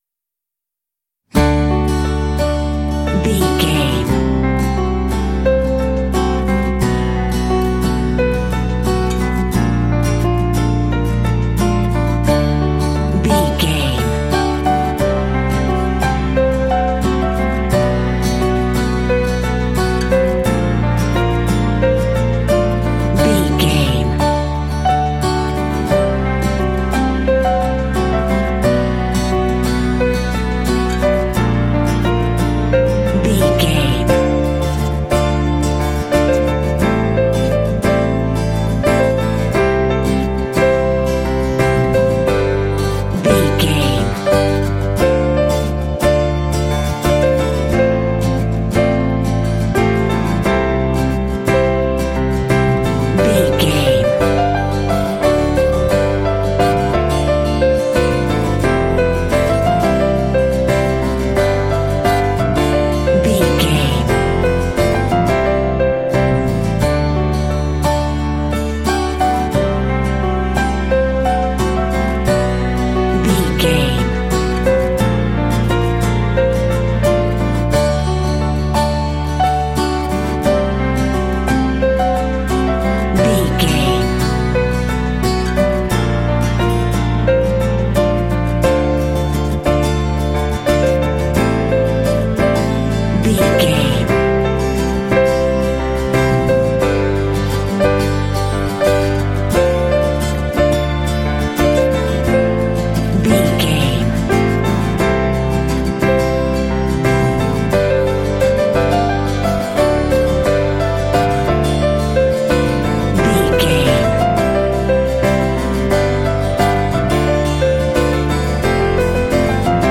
Ionian/Major
light
dreamy
sweet
orchestra
horns
strings
percussion
cello
acoustic guitar
cinematic
pop